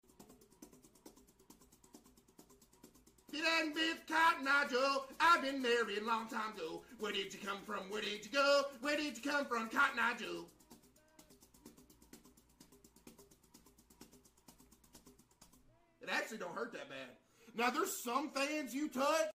CaseOh starts singing Cotton Eye Joe while putting his hand on the fan blades as its spinning